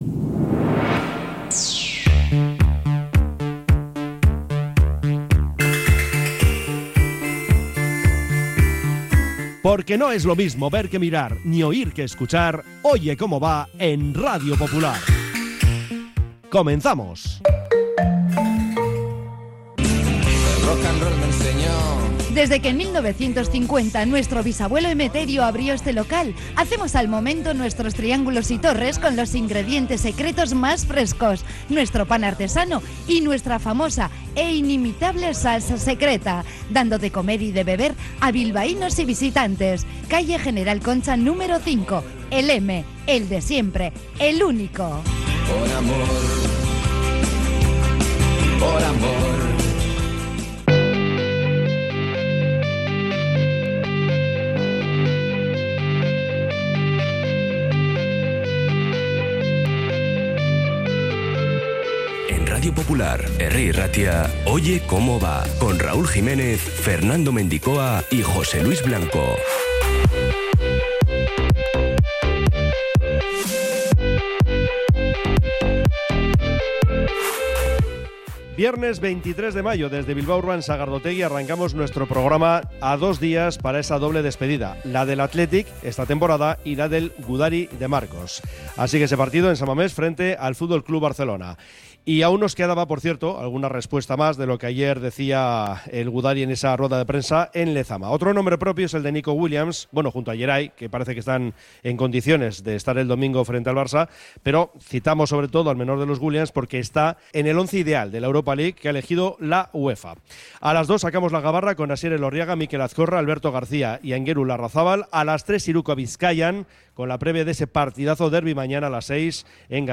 Tramo informativo de 13.30 a 14h